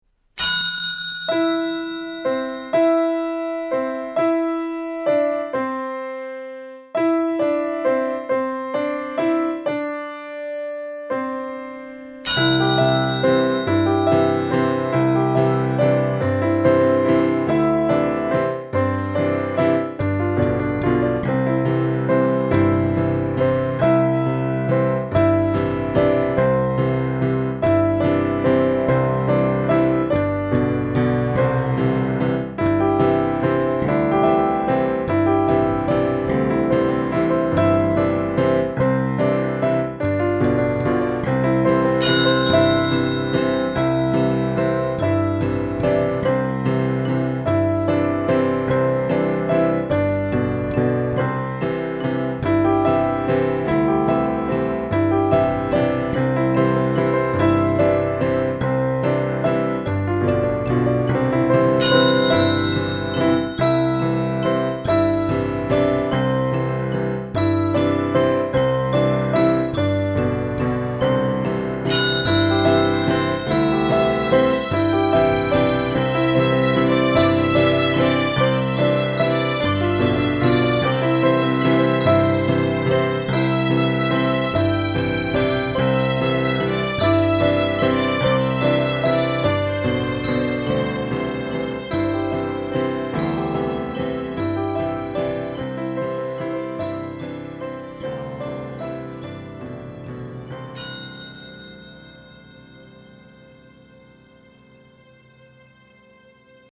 Song Demos
incidental music